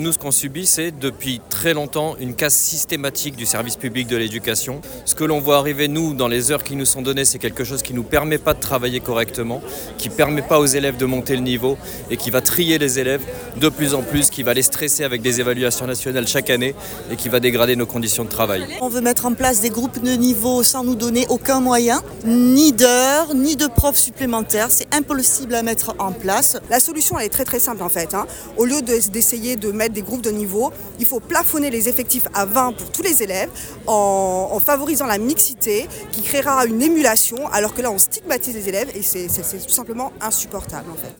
Trois enseignants d'un collège de la région sont en colère après les annonces du ministère de l'Education.
son-greve-enseignants-en-colere-22400.mp3